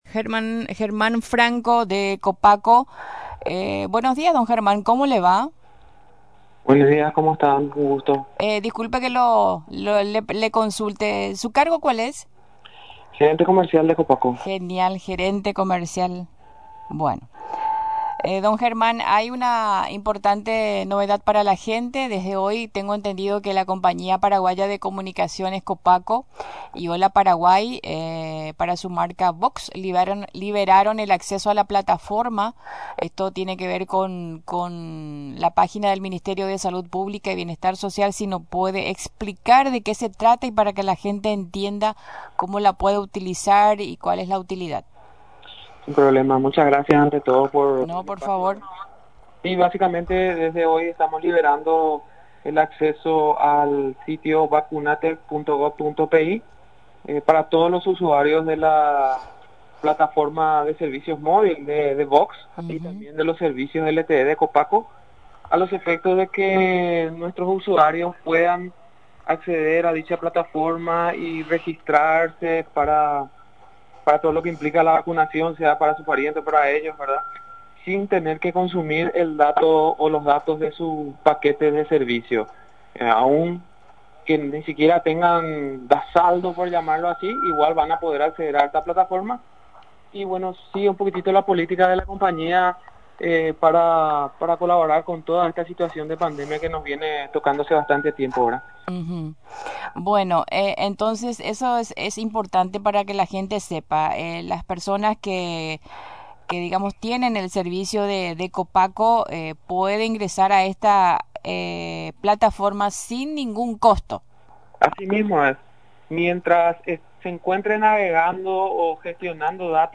en comunicación con «Paraguay Puede» de Radio Nacional del Paraguay.